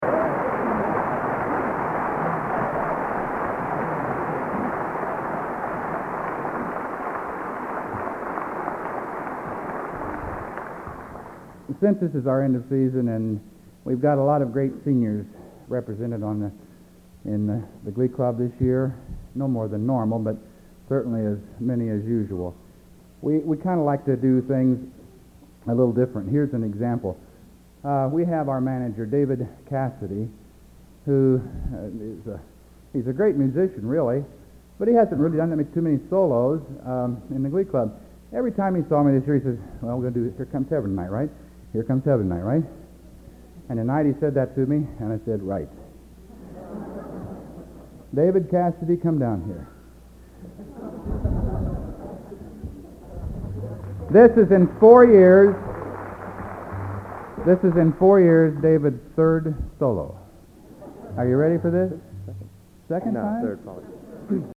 Location: West Lafayette, Indiana